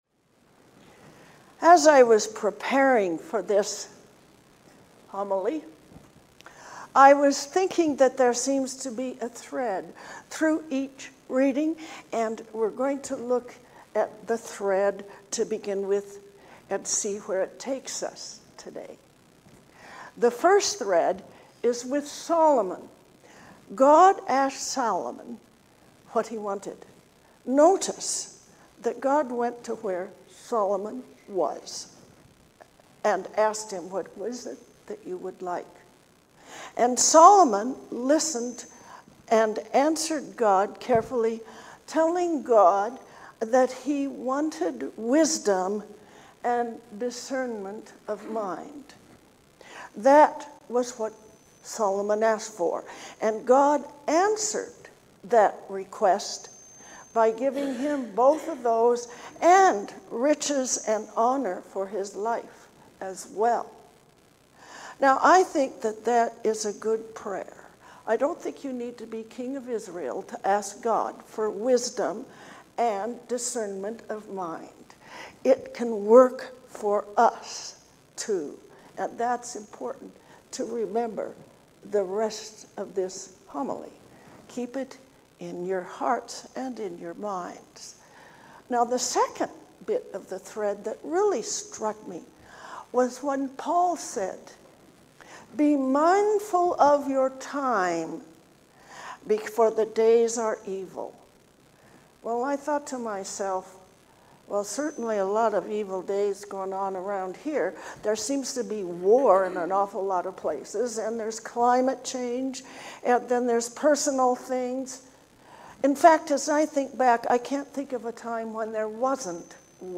A Life of Discernment. A Sermon for the 13th Sunday after Pentecost.